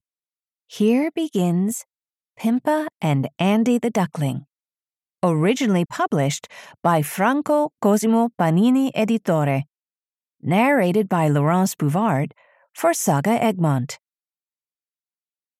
Audio knihaPimpa - Pimpa and Andy the Duckling (EN)
Ukázka z knihy